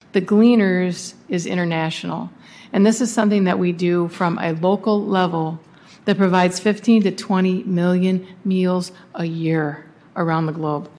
Kalamazoo Vice Mayor Jeanne Hess says the organization is very much in the spirit of the holidays.